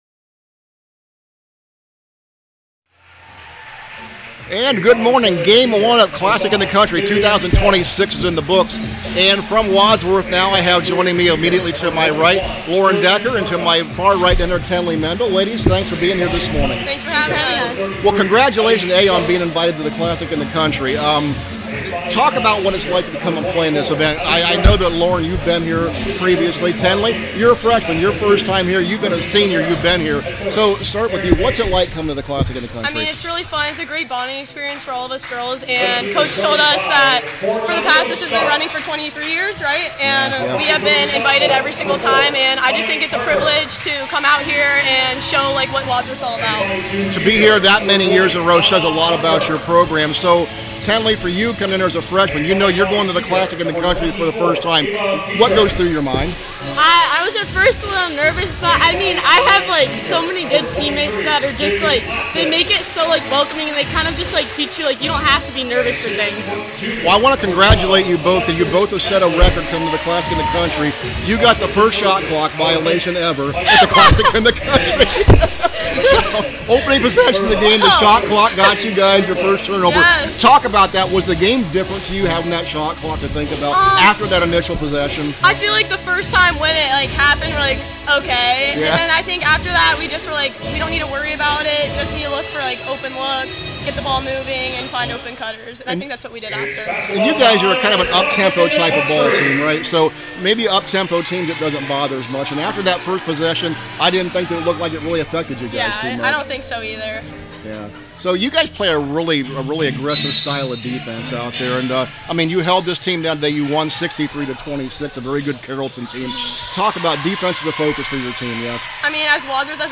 2026 CLASSIC – WADSWORTH PLAYER INTERVIEWS